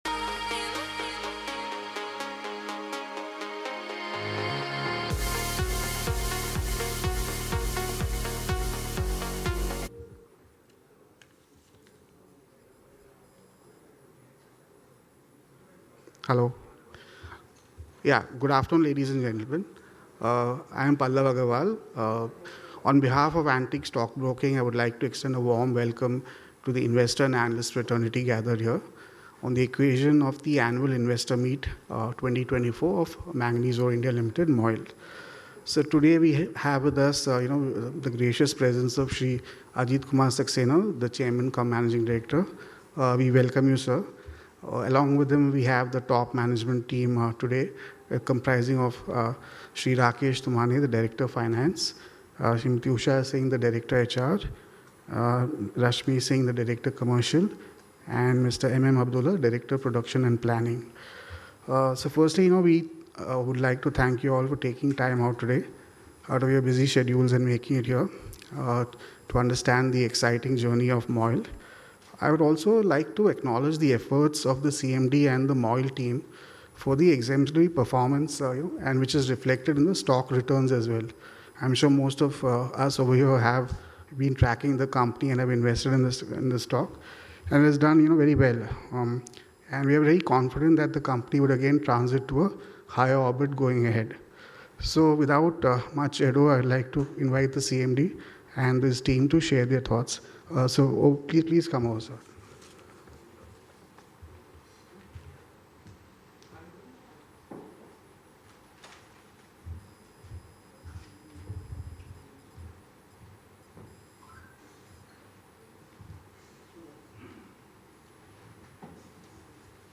23/08/2024 - Invertors and Analysts Meet-2024 (Audio)